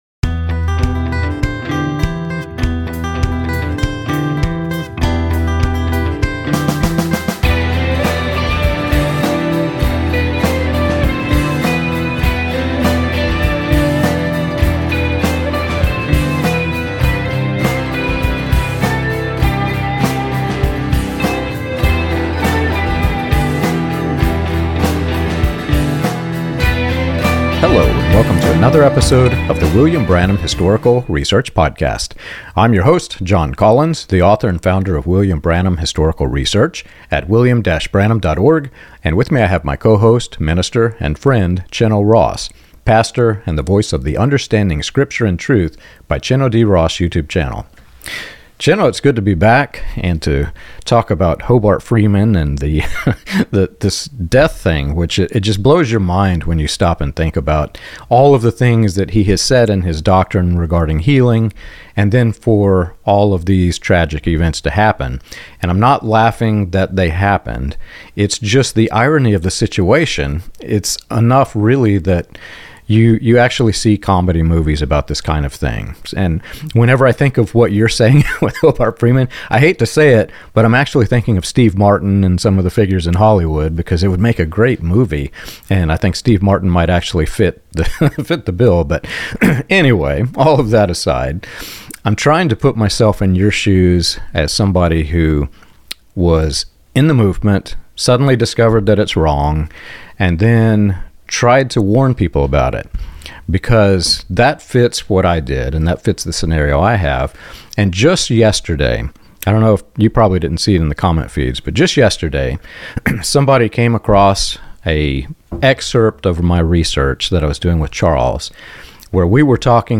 This conversation explores the psychological and spiritual mechanisms that sustain high-control religious systems, including cognitive dissonance, leader immunity, and myth-making after a leader's death.